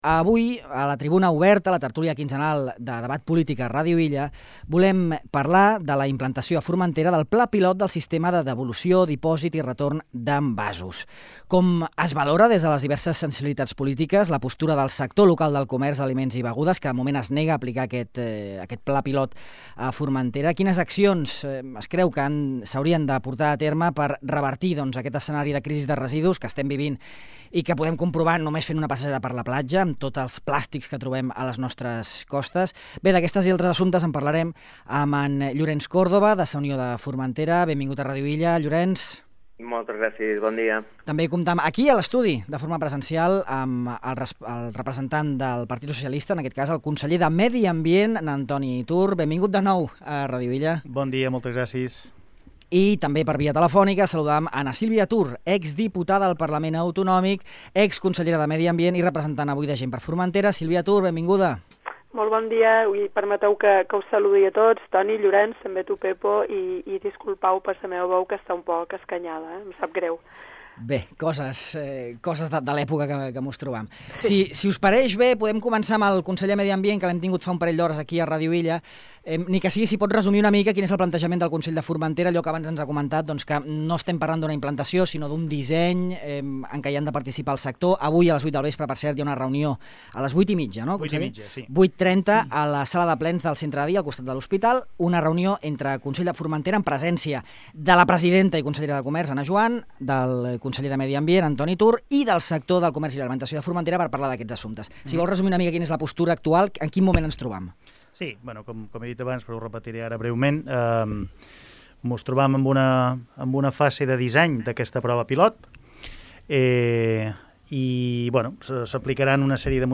El Sistema de Devolució d’Envasos, a debat a la tertúlia política
La Tribuna Oberta, la tertúlia política quinzenal de Ràdio Illa, tracta avui la implantació a Formentera del pla pilot del Sistema de Dipòsit, Devolució i Retorn (SDDR) d’Envasos. En el debat participen Antoni Tur, conseller de Medi Ambient (PSOE), Llorenç Córdoba, conseller de Sa Unió de Formentera, i Sílvia Tur, exdiputada i exconsellera de Medi Ambient (GxF).